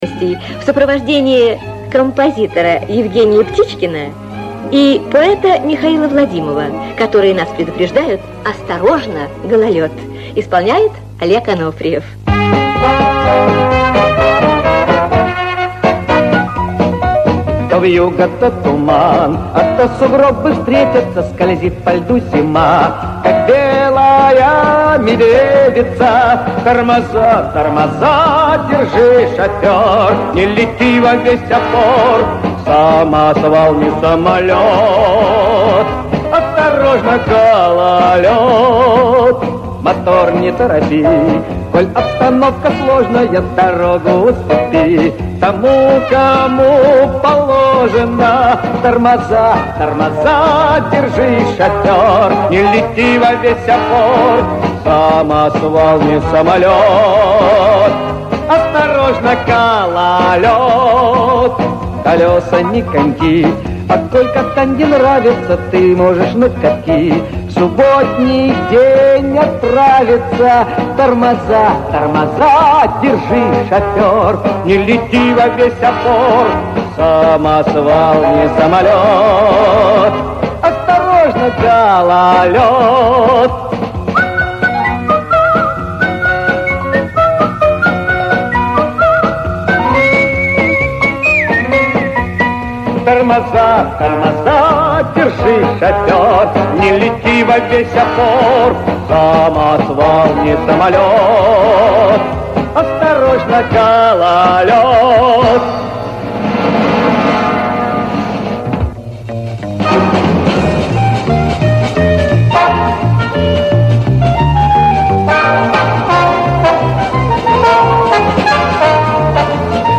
Вложения Всесоюзное радио СССР — С добрым утром. Радиопередача 1968 года..mp3 Всесоюзное радио СССР — С добрым утром.